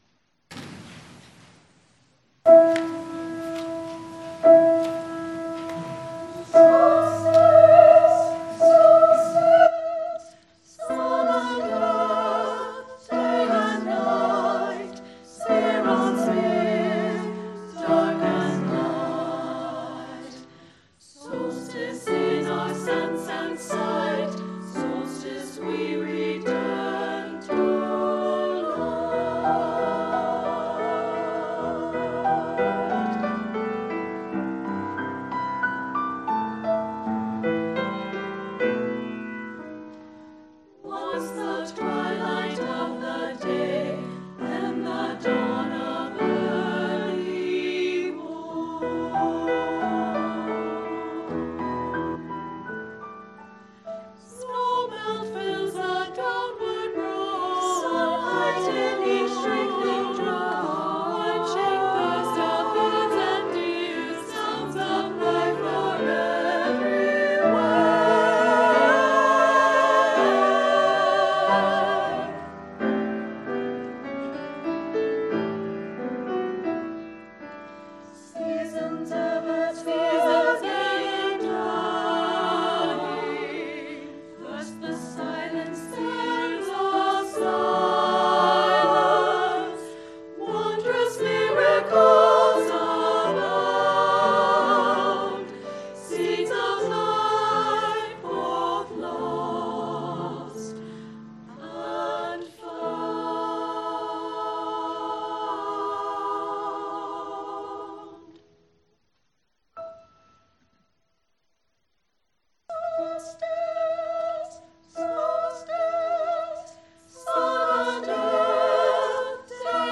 Choir and Instrumental Music
“Solstice” by John Purifoy as performed December 29, 2019 by UUSS Chalice Singers.